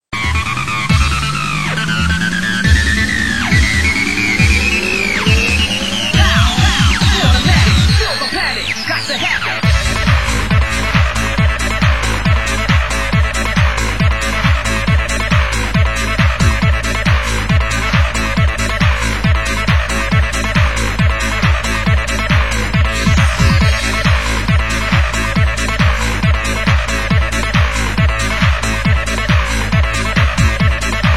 Genre: Hard House
Genre: Trance